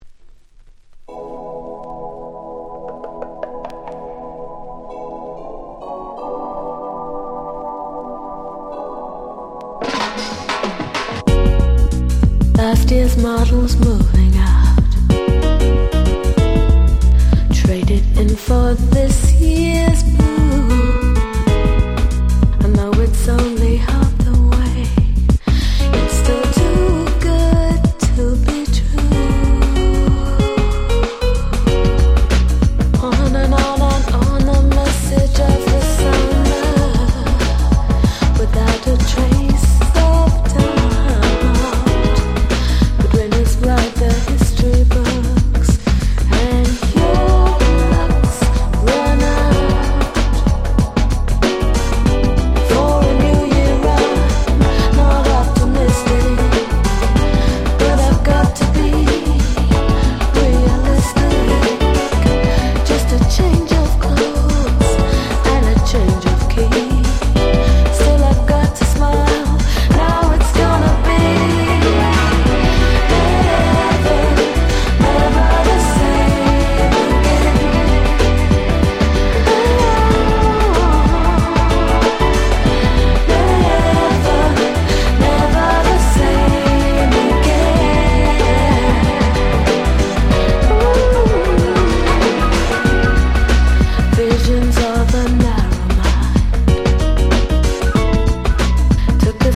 最高のUK Soul Albumです。